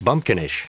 Prononciation du mot bumpkinish en anglais (fichier audio)
Prononciation du mot : bumpkinish